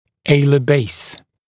[‘eɪlə beɪs][‘эйлэ бэйс]плоскость крыльев носа